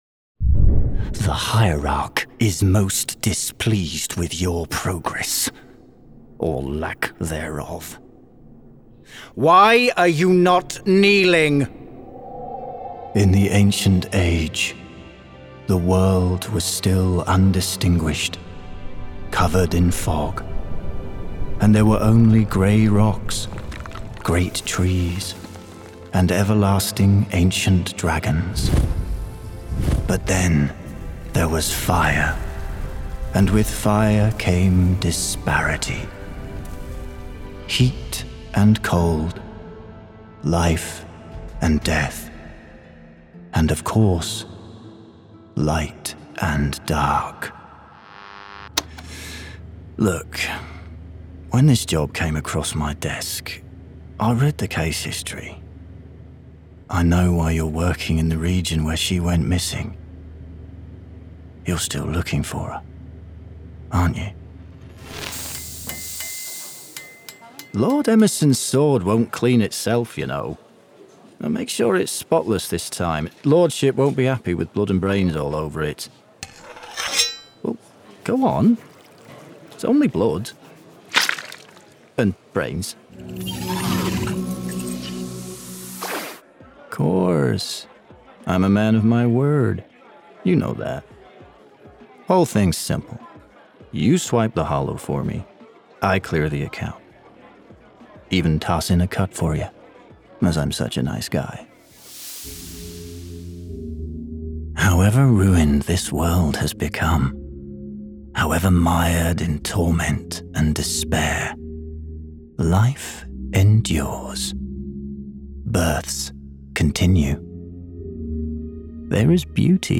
Native Accent: RP Characteristics: Friendly and Informative Age: 20-30 Age: 30-40 View on spotlight Commercial Corporate Audiobook Documentary